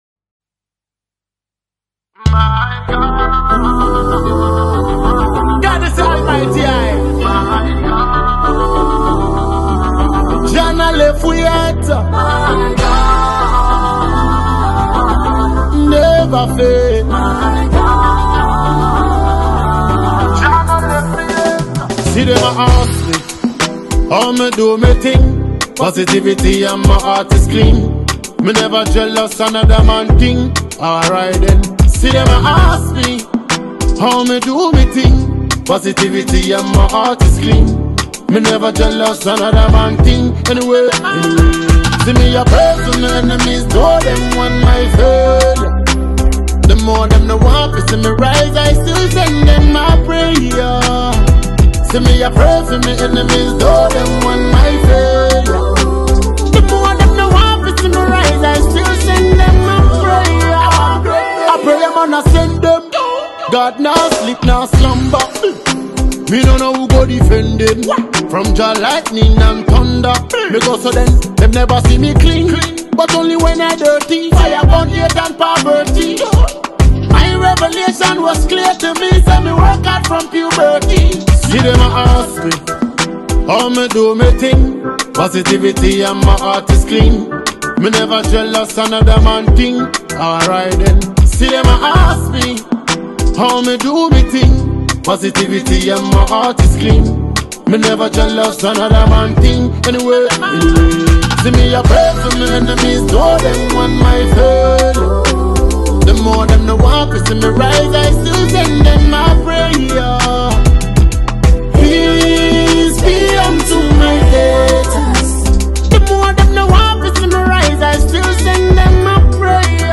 afrobeat dancehall music